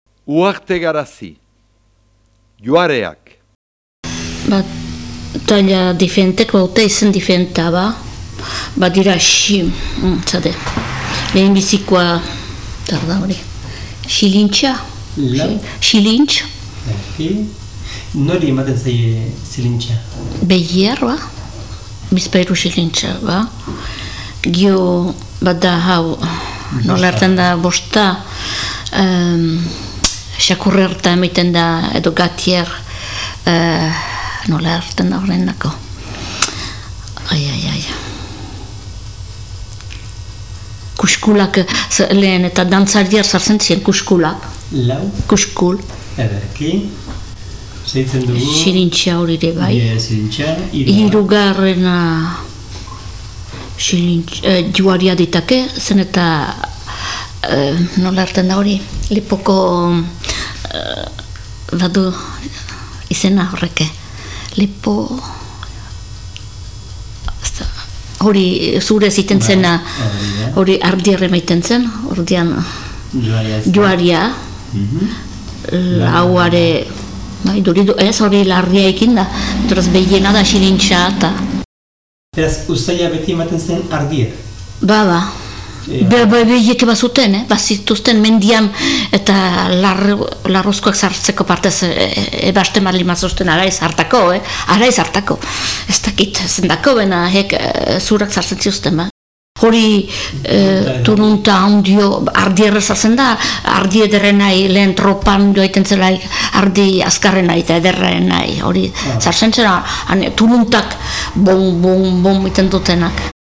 sudurkarizazio adierazkorra entzuten da, aipatu tresna zinez handiago dela adierazten baita.